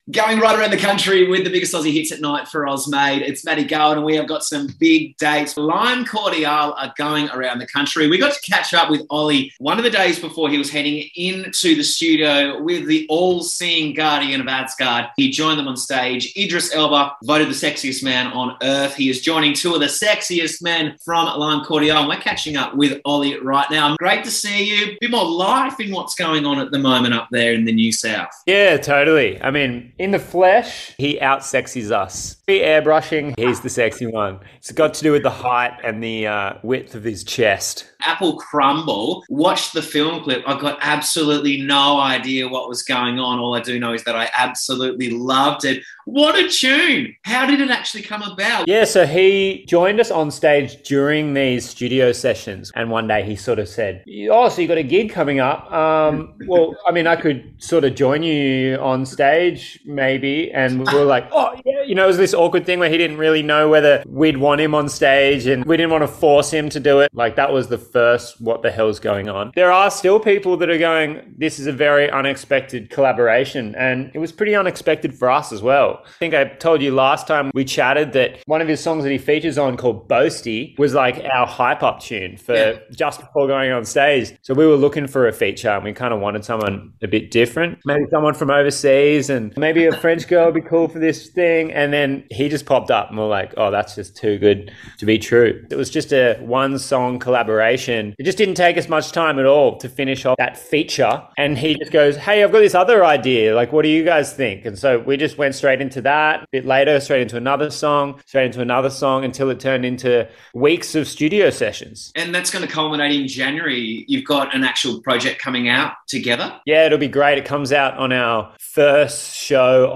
jaunty new single